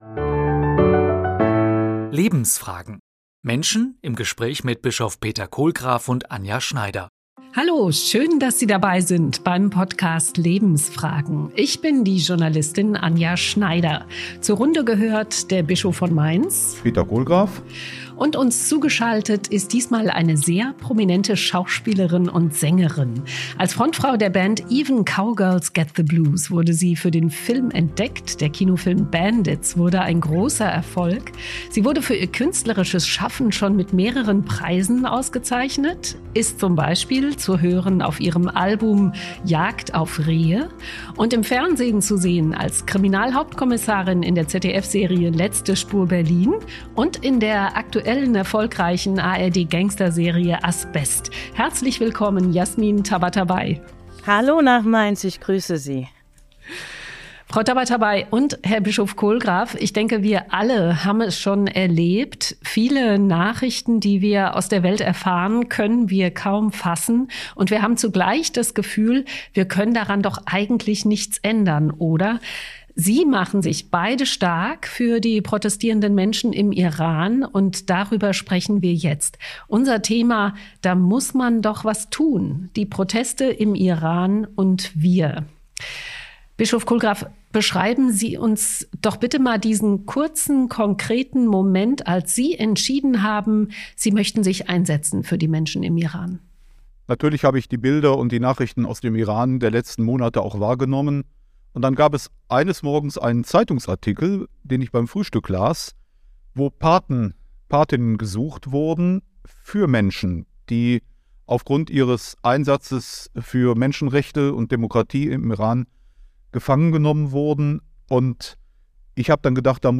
Menschen im Gespräch
Zu Gast: Jasmin Tabatabai.